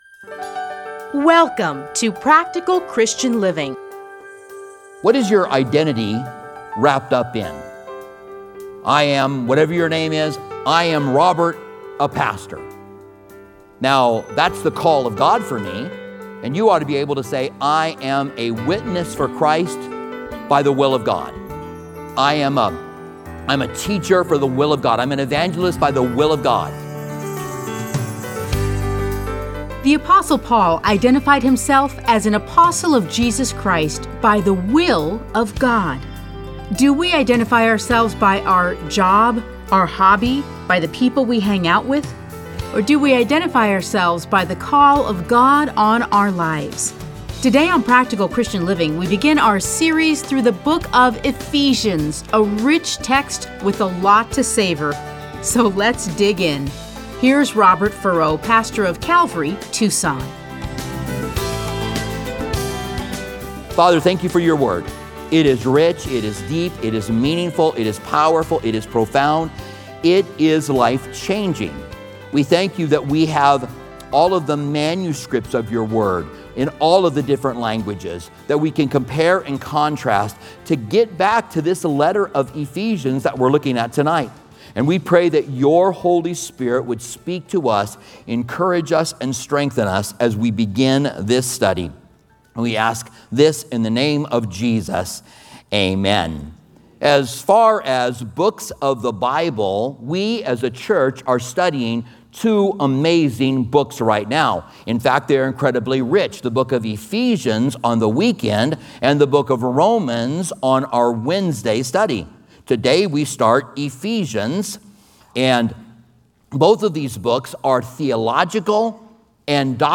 Listen to a teaching from Ephesians 1:1-10.